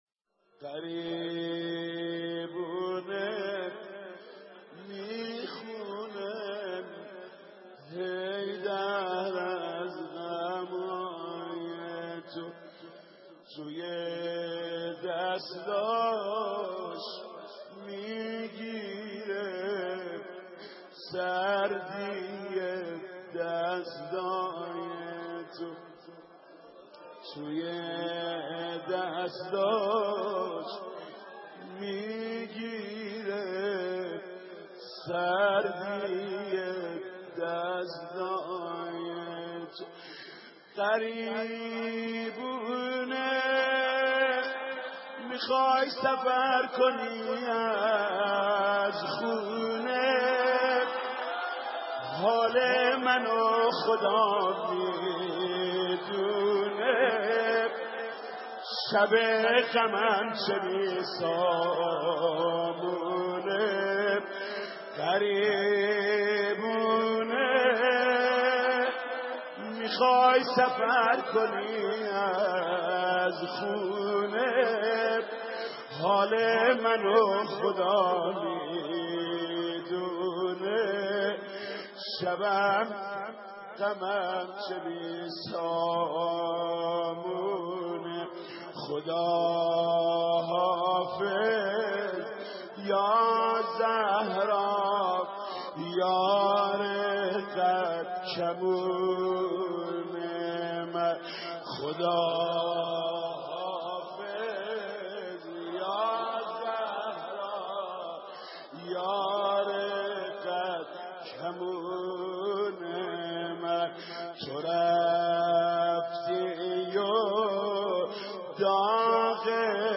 مرثیه خوانی